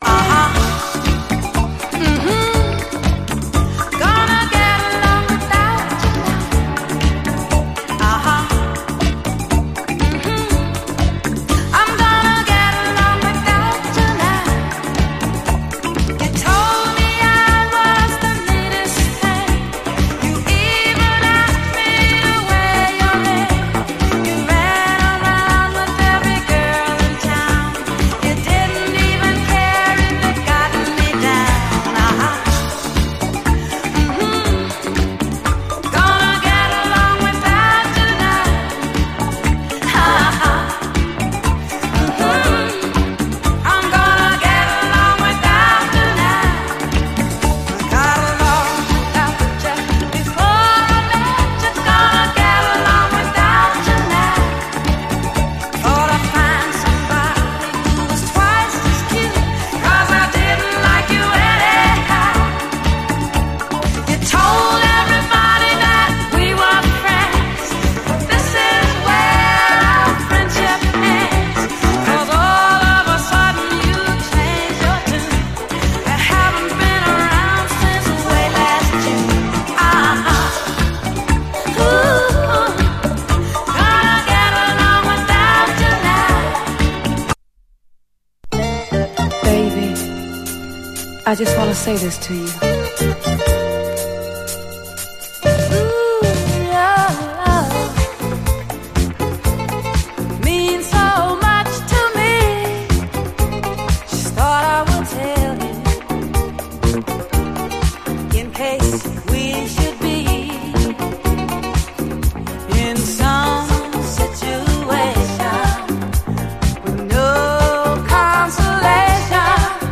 SOUL, 70's～ SOUL, DISCO, 7INCH
♪アハ、ンフ、のフレーズでお馴染み、幸福感で満たす最高のキュート・ディスコ・クラシック！
心地よいこみ上げ系グレイト・トロピカル・ステッパー